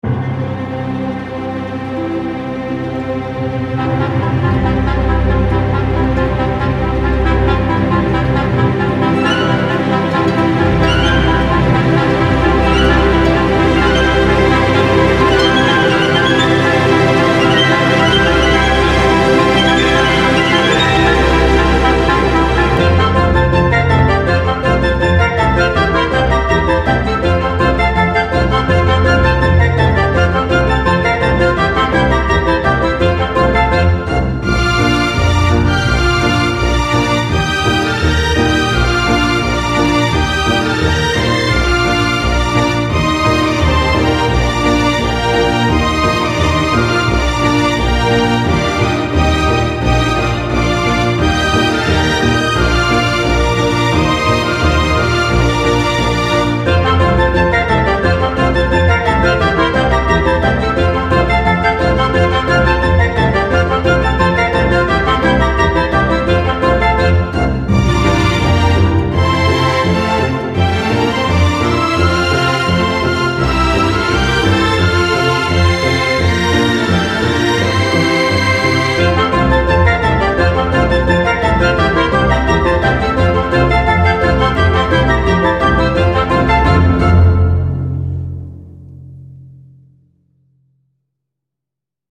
angoissant - classique - melancolique - nostalgique - valse